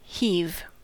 Ääntäminen
IPA: /ˈheːbən/ IPA: [ˈheːbm̩]